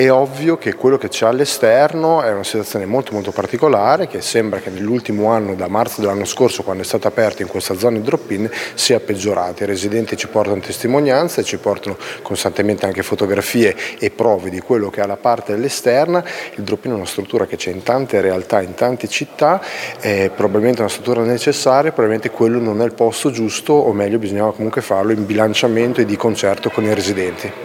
Sentiamo Piergiulio Giacobazzi di Forza Italia: